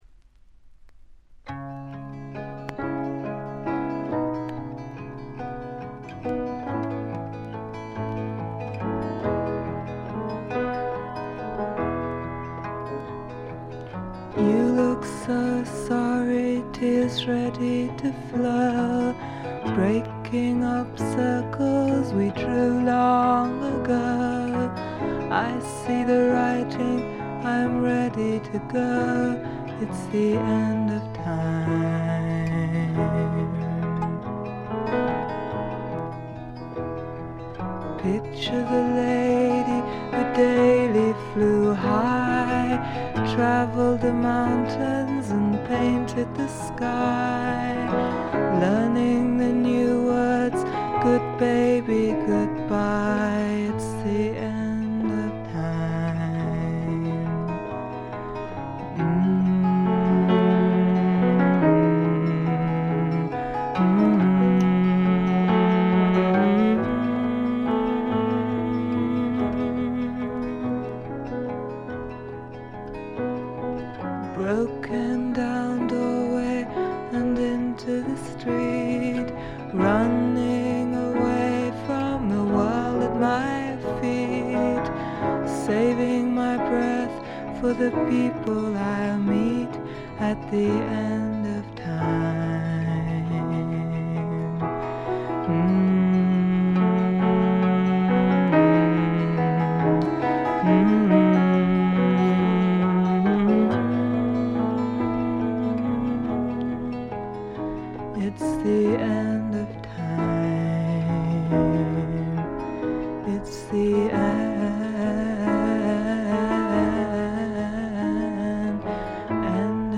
A4冒頭とB1中盤で2連のプツ音。
試聴曲は現品からの取り込み音源です。
Piano